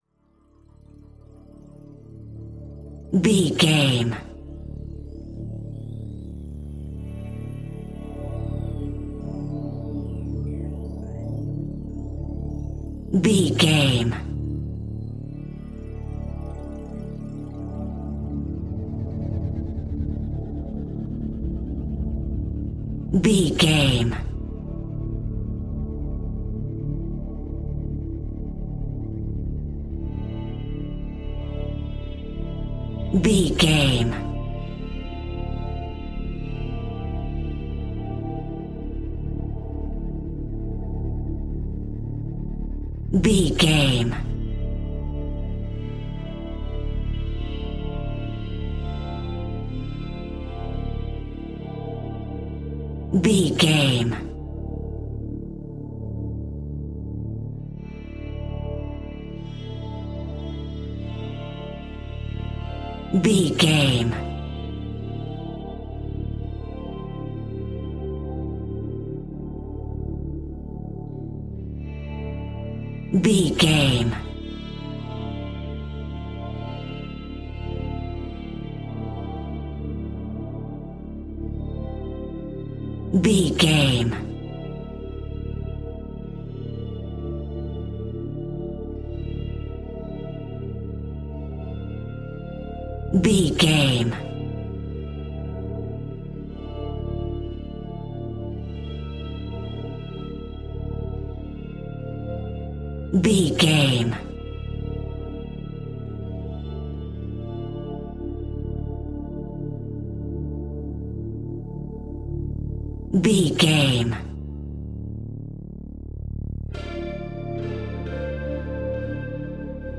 Thriller
Aeolian/Minor
Slow
synthesiser
electric guitar
drums